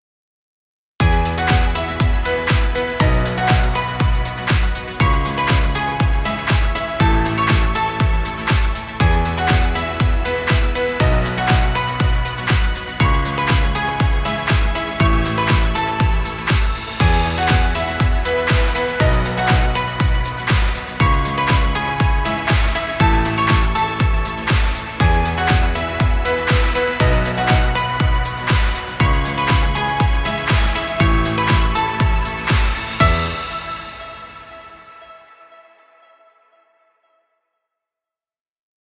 Happy motivational music. Background music Royalty Free.